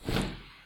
snd_ui_cancel.wav